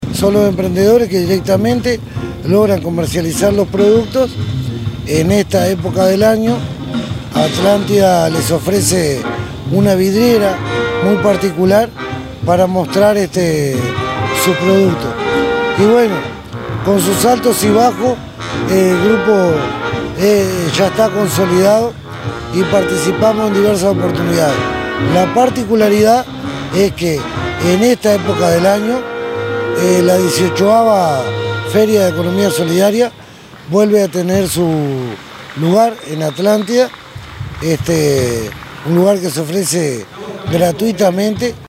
gustavo_gonzalez_-_alcalde_de_atlantida_0.mp3